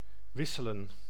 Ääntäminen
IPA: [ʋɪ.sələⁿ]